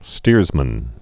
(stîrzmən)